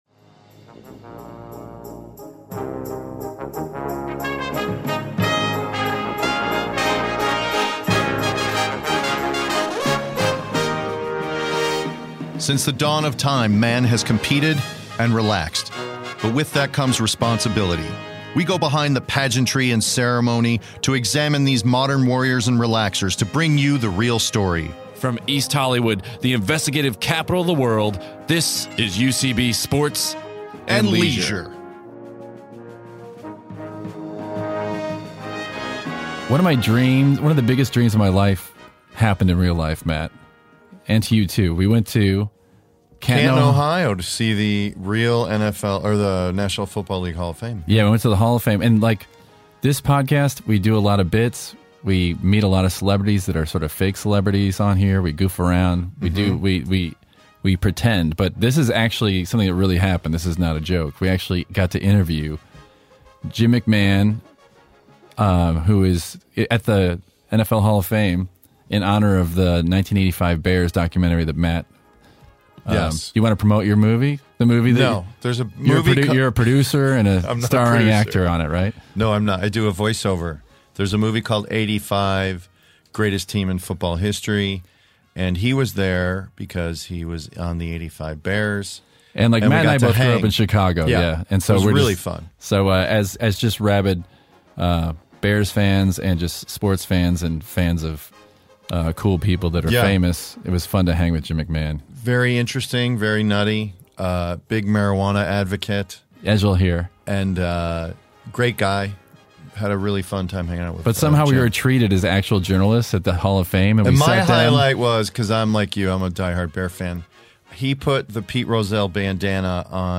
travel to the NFL Hall of Fame to interview two-time Super Bowl winner Jim McMahon.